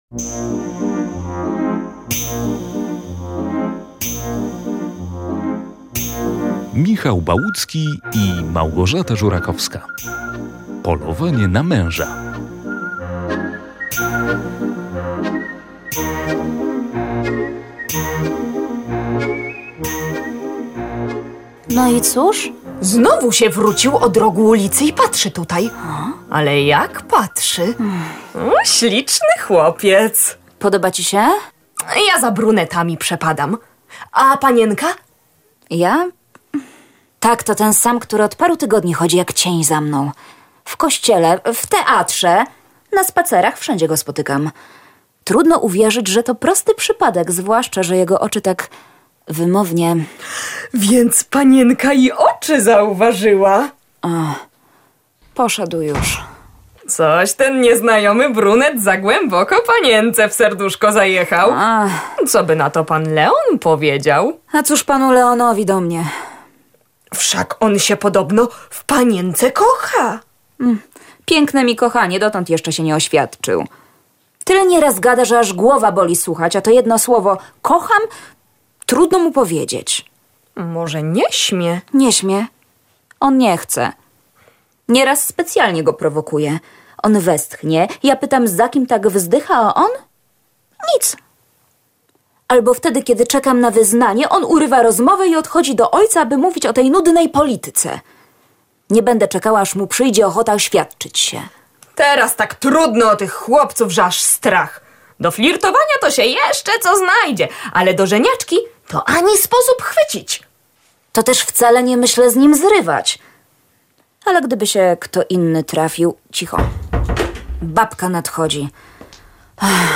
W audycji zapraszamy do wysłuchania słuchowiska Małgorzaty Żurakowskiej na podstawie komedii Michała Bałuckiego „Polowanie na męża”. To farsa o aspiracjach mieszczanina do warstw arystokracji z targami małżeńskim w tle.